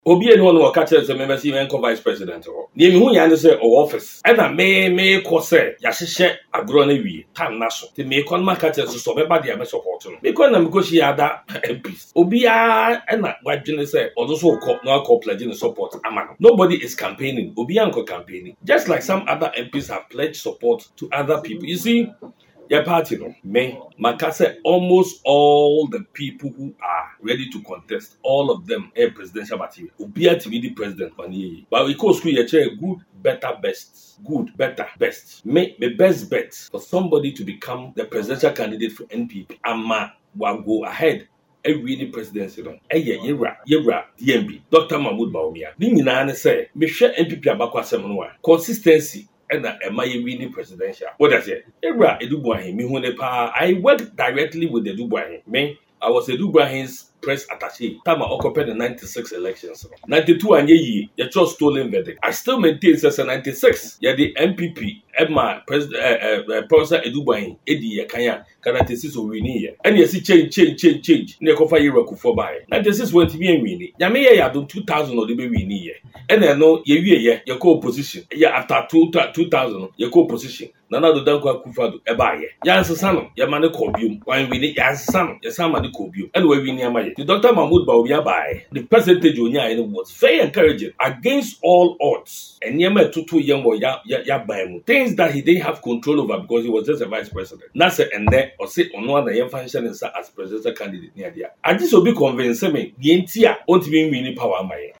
Speaking in an exclusive interview with Puretvonline, the MP emphasised the importance of consistency in political strategy, citing historical examples within the NPP where perseverance eventually paid off.